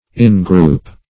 in-group \in"-group`\, in group \in" group`\n.